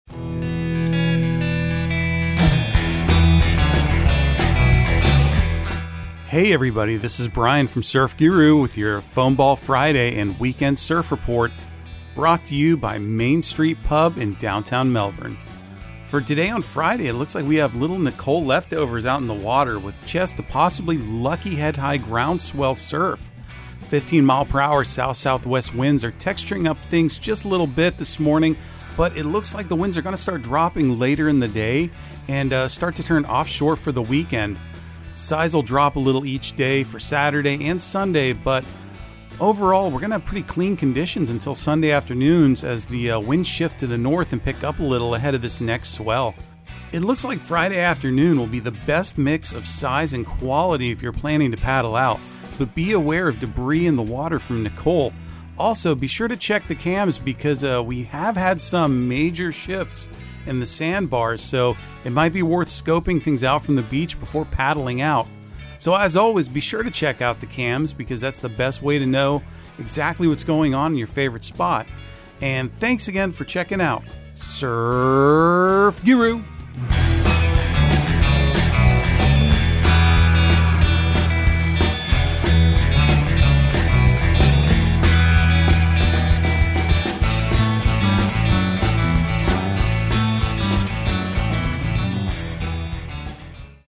Surf Guru Surf Report and Forecast 11/11/2022 Audio surf report and surf forecast on November 11 for Central Florida and the Southeast.